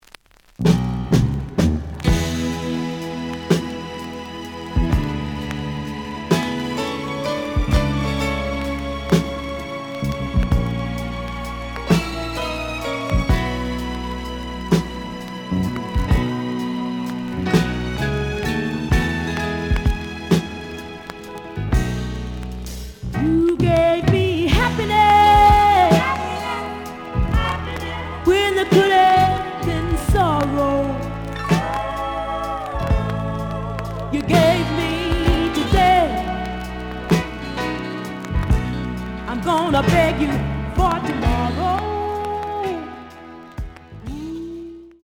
The audio sample is recorded from the actual item.
●Genre: Soul, 70's Soul
Some noise on A side.